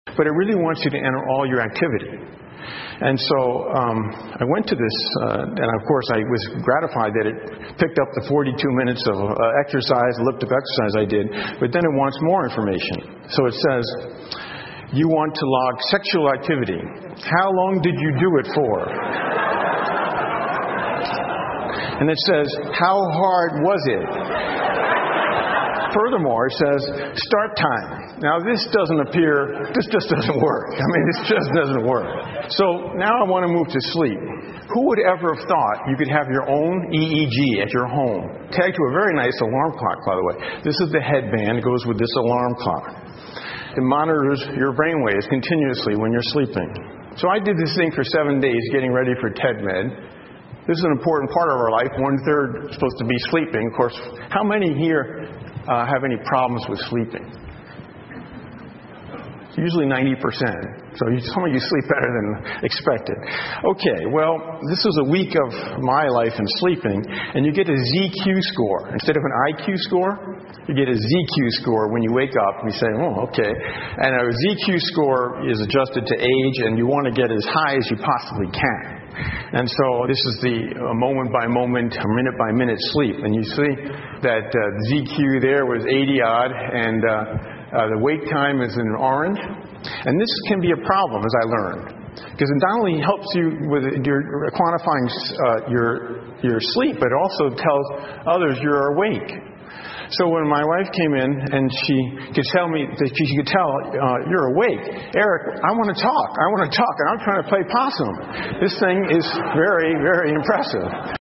TED演讲:未来医疗的无线化() 听力文件下载—在线英语听力室